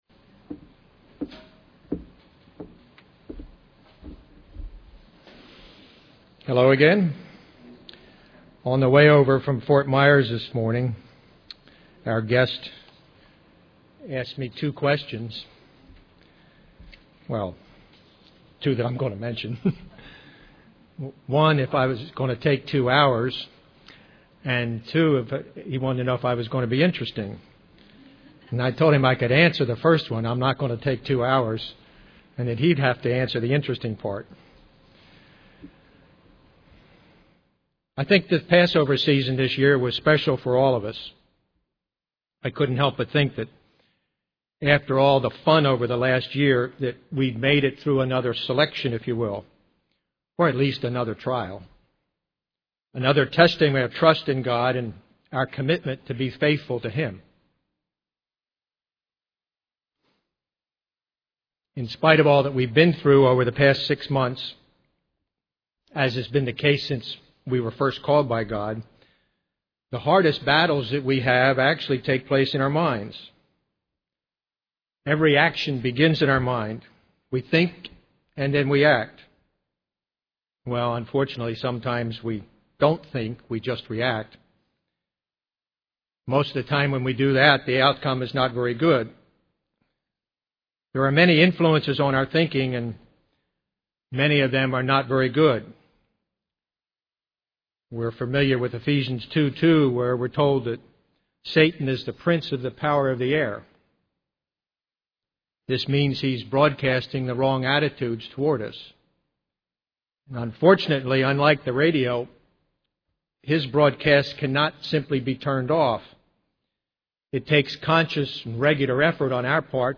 Given in Ft. Lauderdale, FL
UCG Sermon Studying the bible?